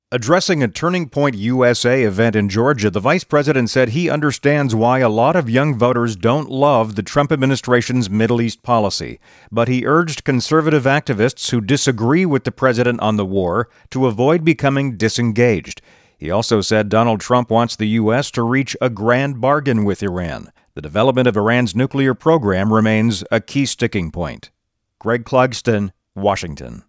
Vice President Vance admits that many young Americans don’t support the U-S-led war against Iran. Addressing a Turning Point USA event in Georgia, the vice president said he understands why “a lot of young voters don’t love” the Trump administration’s Middle East policy. But he urged conservative activists who disagree with the president on the war to avoid becoming “disengaged.”